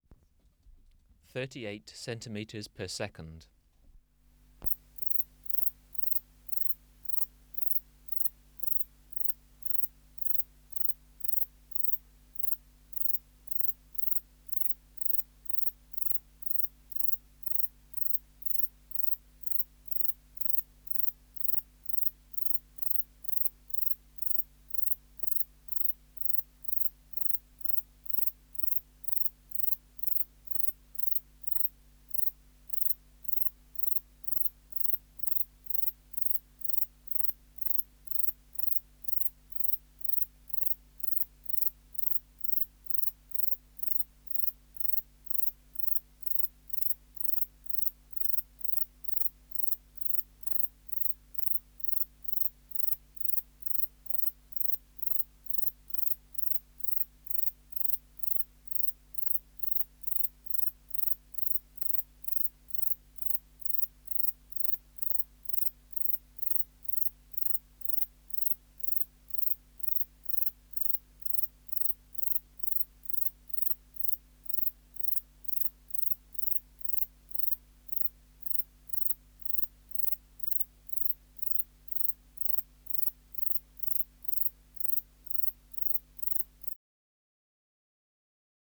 405: 8 Platycleis sabulosa (472b) | BioAcoustica
Recording Location: BMNH Acoustic Laboratory
Reference Signal: 1 kHz for 10 s
Substrate/Cage: Large recording cage
Distance from Subject (cm): 50